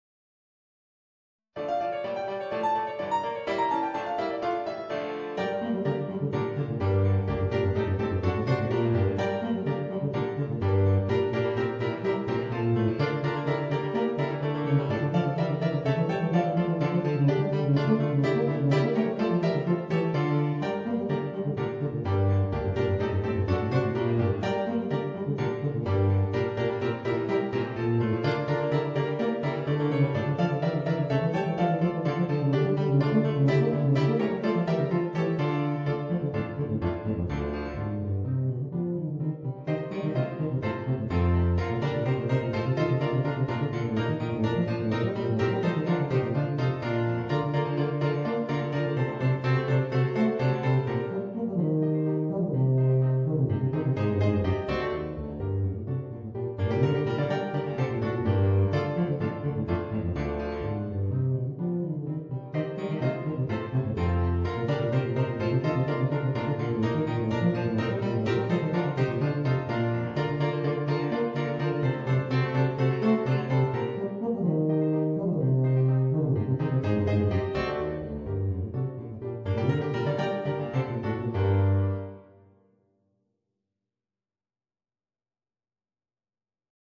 Voicing: Eb Bass and Piano